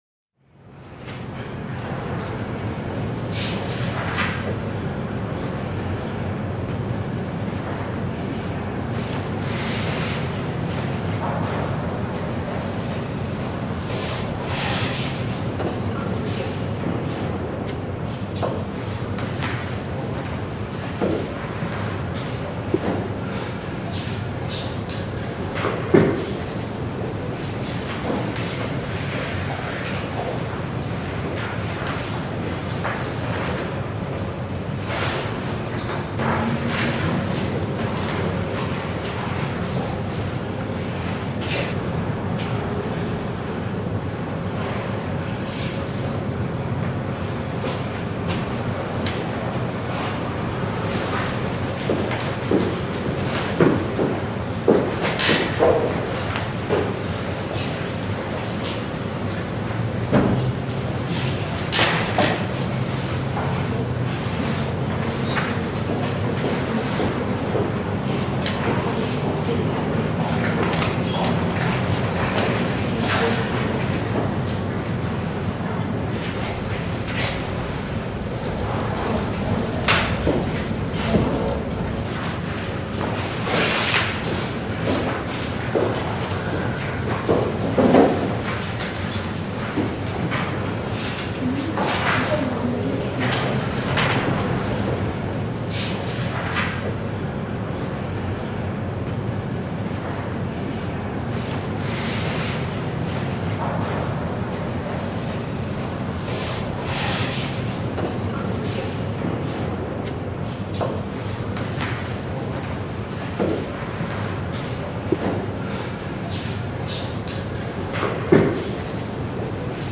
1 Hour Quiet Library Timer for Deep Academic Focus
Study Sounds, Background Sounds, Programming Soundscapes